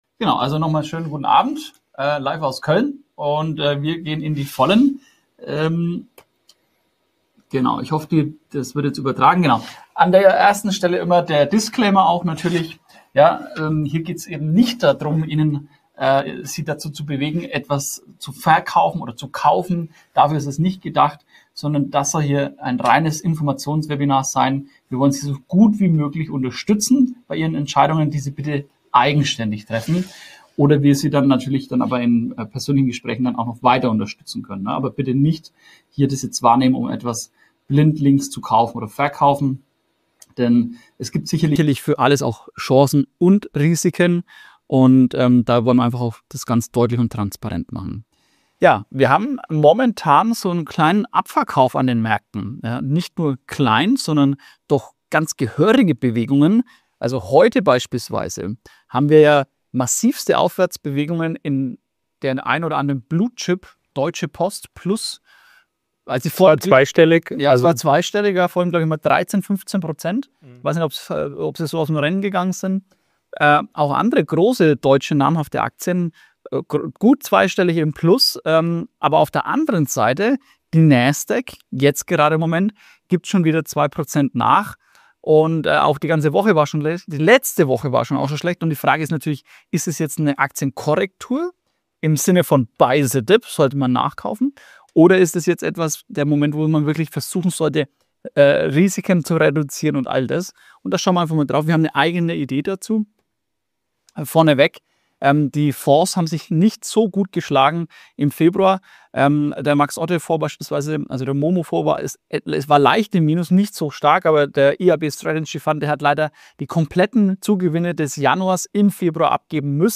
Goldausblick 2025: Webinar